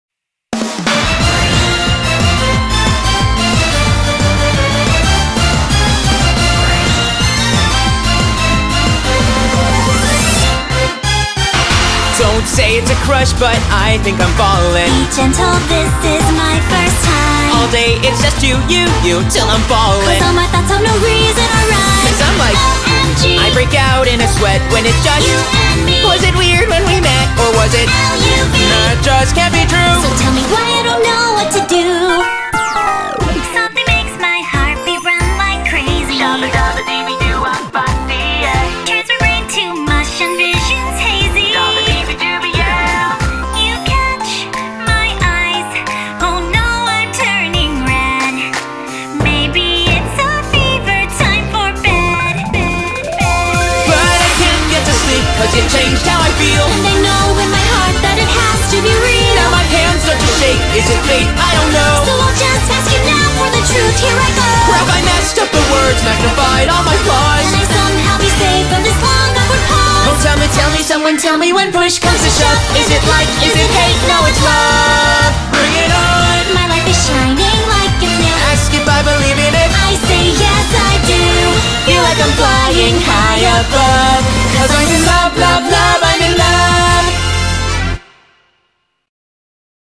BPM90-180
Audio QualityCut From Video